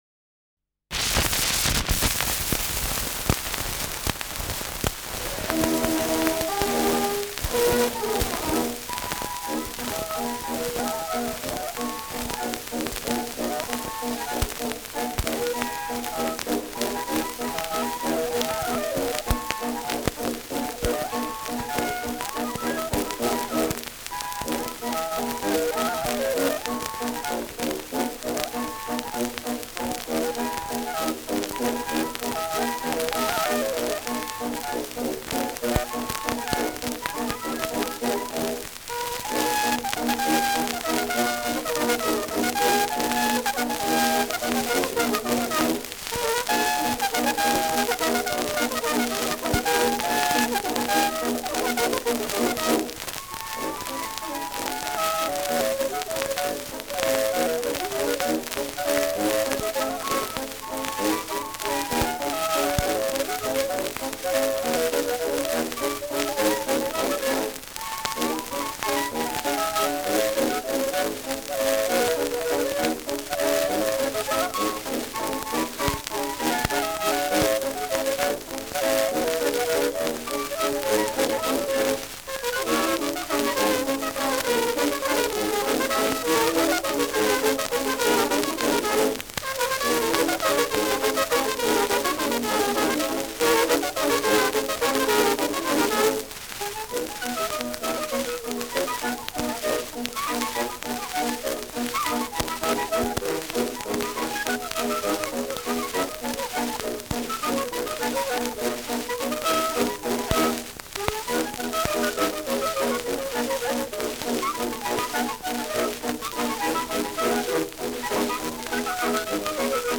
Schellackplatte
Starkes Grundrauschen : Durchgehend stärkeres bis starkes Knacken : Nadelgeräusch : Verzerrt an lauteren Stellen